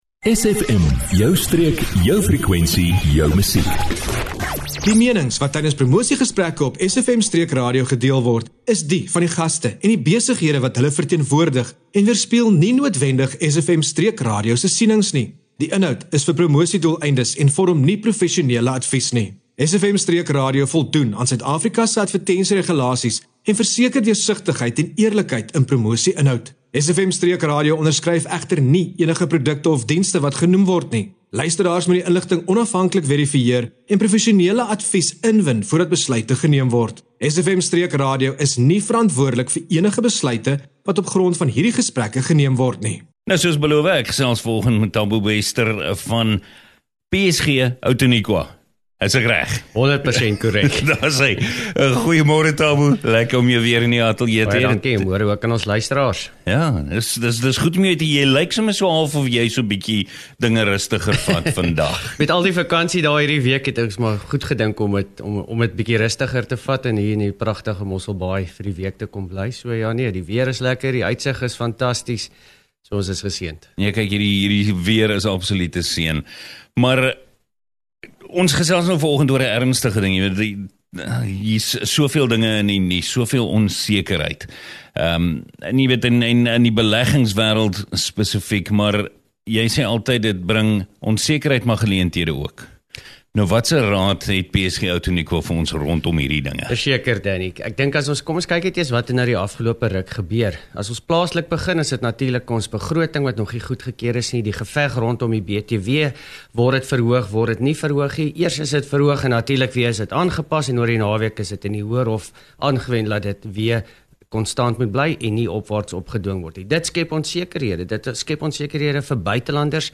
29 Apr PROMOSIE: PSG Outeniqua onderhoud 29 April 2025